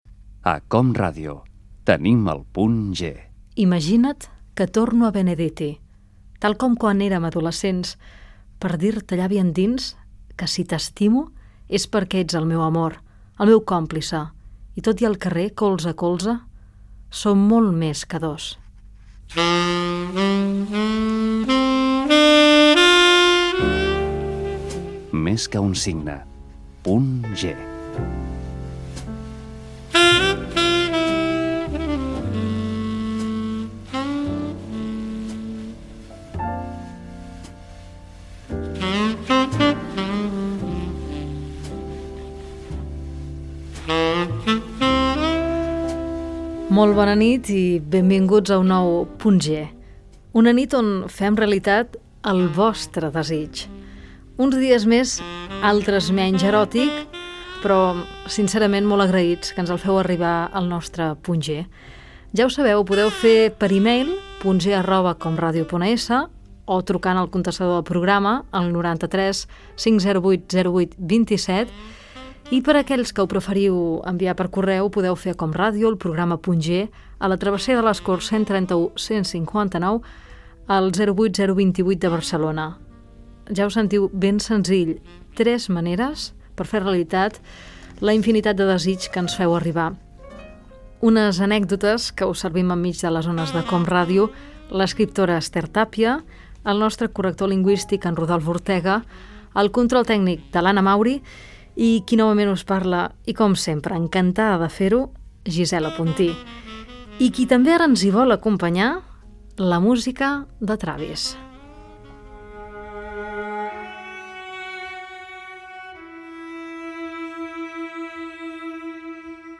Identificació del programa, text, careta del programa, presentació, formes de contactar amb el programa i crèdits del programa
Entreteniment
FM